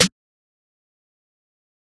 Snare 10 [ dro layer ].wav